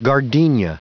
Prononciation du mot gardenia en anglais (fichier audio)
Prononciation du mot : gardenia